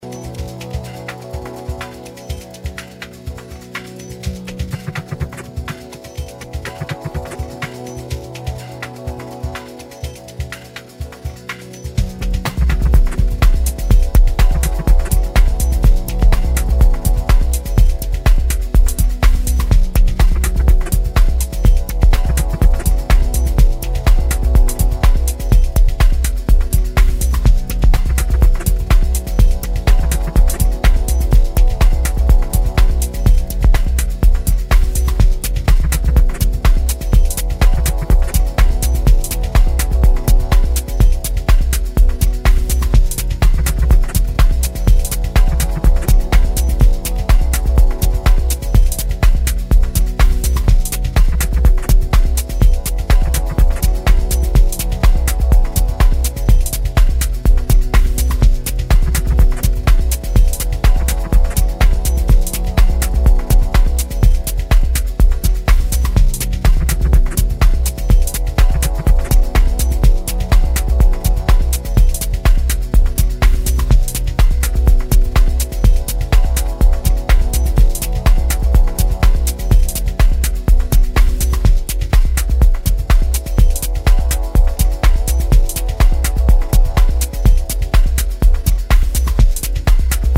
Well Crafted Dark Minimal Bombs